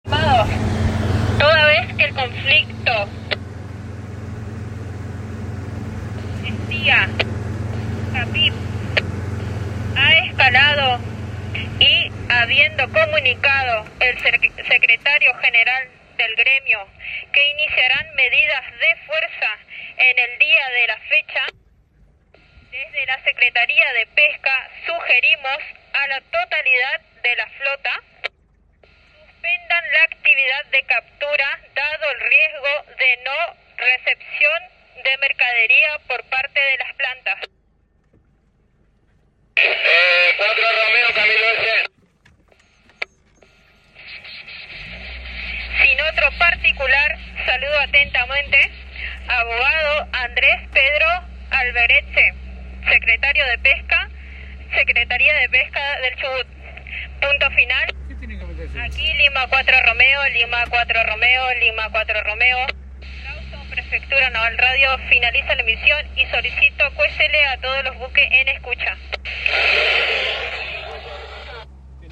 Apenas conocida esta asamblea, desde la Secretaría de Pesca que conduce Andrés Arbeletche se transmitió una comunicación a toda la flota pesquera, a través de la Prefectura, para recomendarles que retornen a puerto ya que por la medida de fuerza las plantas no podrían estar recibiendo la mercadería.
PREFECTURA NAVAL EMITE AVISO A LA FLOTA PESQUERA
PNA-emite-aviso-a-la-flota-pesquera-1.mp3